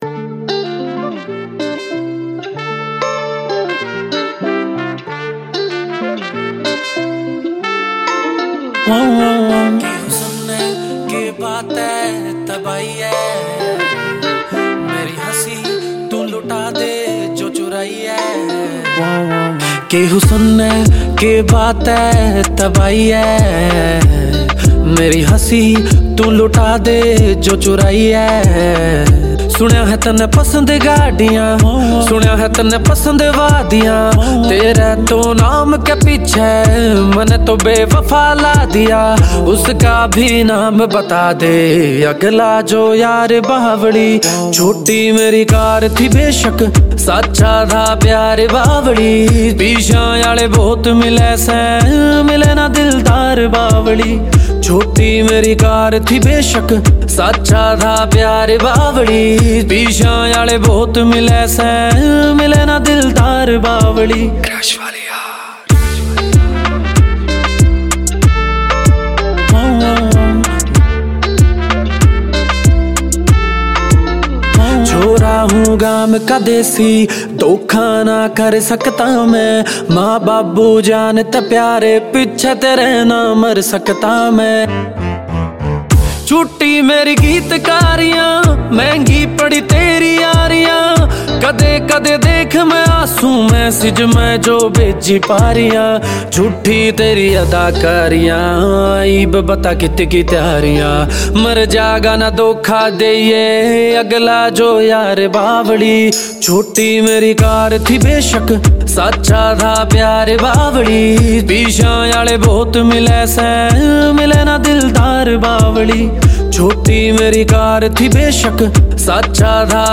Haryanvi Mp3 Songs